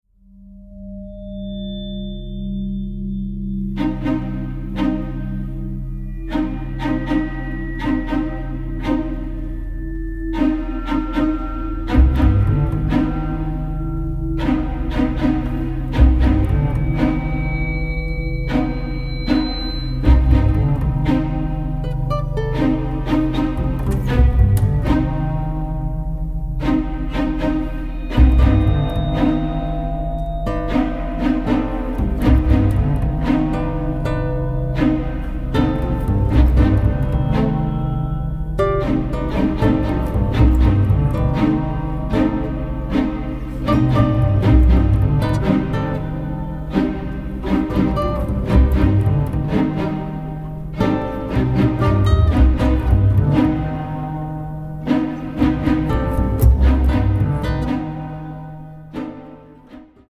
a very emotional and sensitive score
especially cello, harp and guitar.